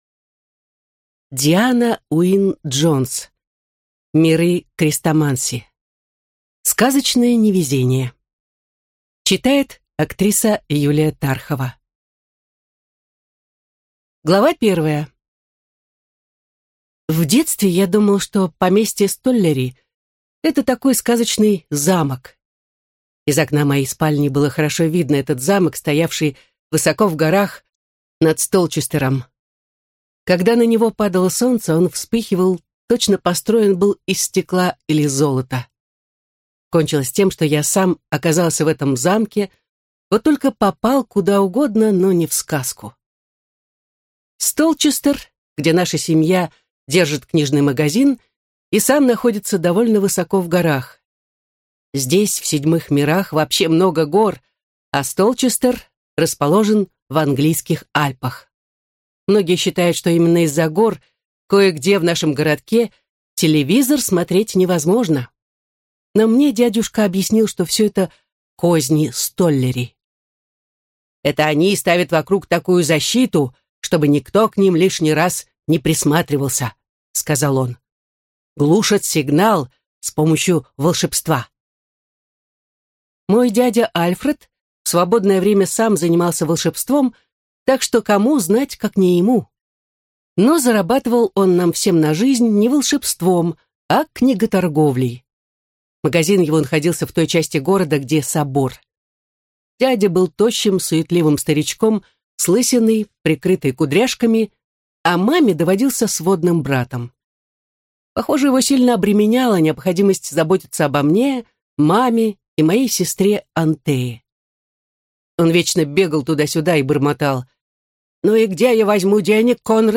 Аудиокнига Сказочное невезение | Библиотека аудиокниг